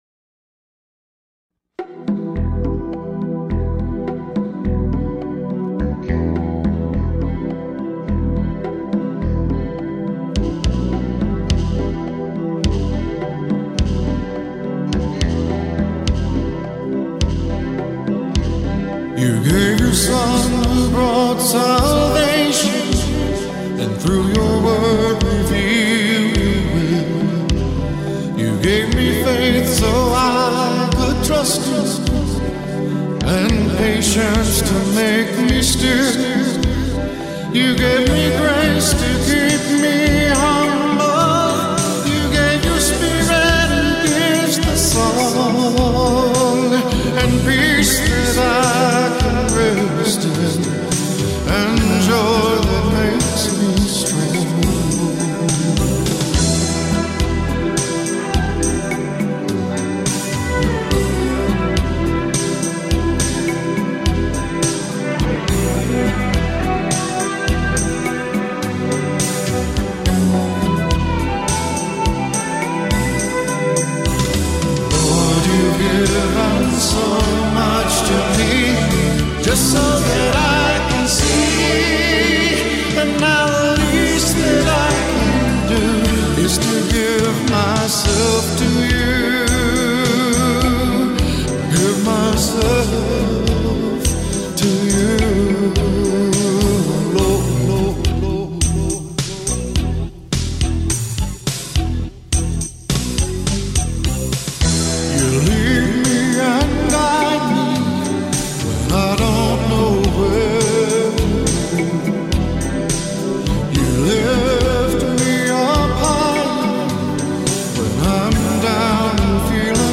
acoustic ballad
with a full band
home studio
I played the guitar and sang lead vocals